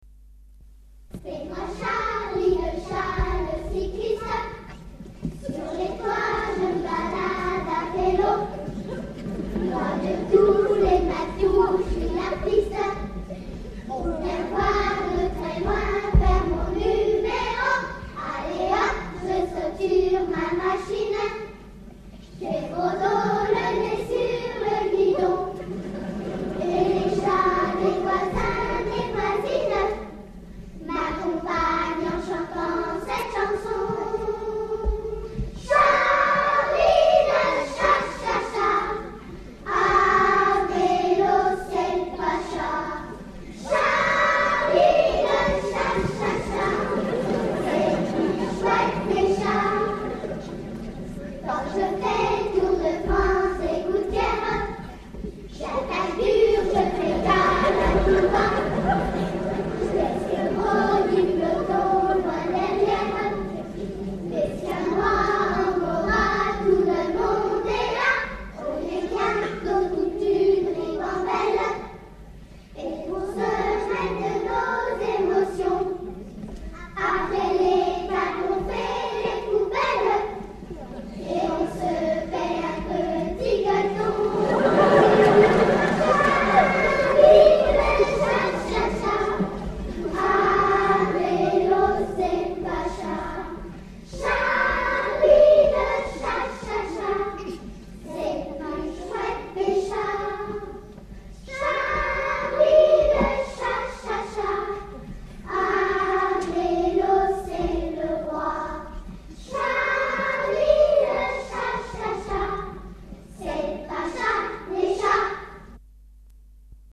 Concert  ACJ Macon CAC 20h30 27 Mai 1988 MACON